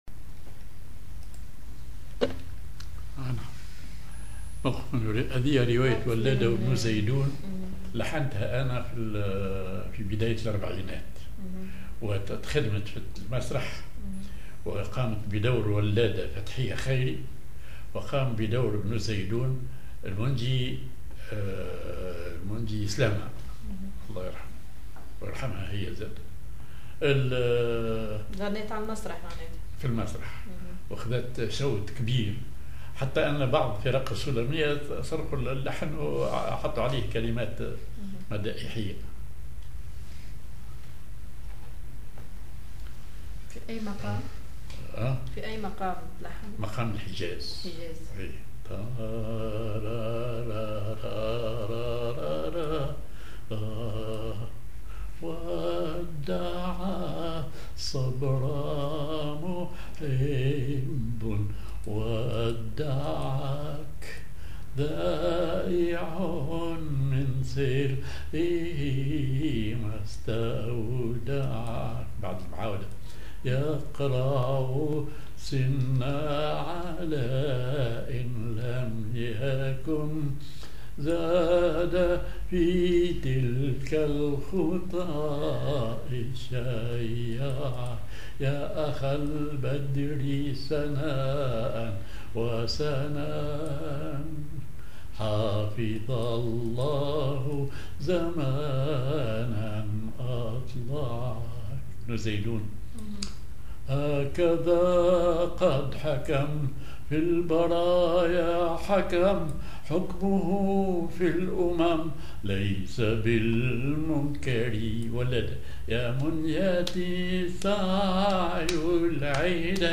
Maqam ar حجاز
genre أغنية